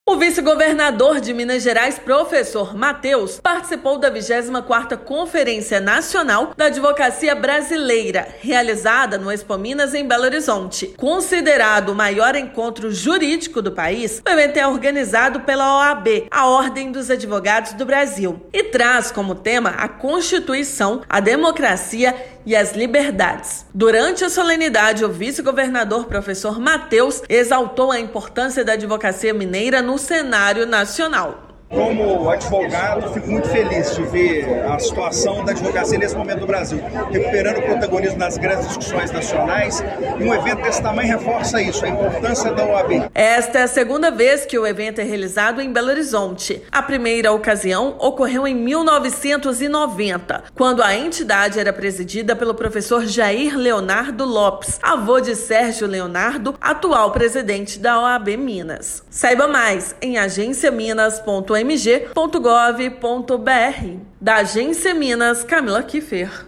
Na manhã desta segunda-feira (27/11), o vice-governador Professor Mateus participou da 24ª Conferência Nacional da Advocacia Brasileira, no Expominas, em Belo Horizonte. Ouça matéria de rádio.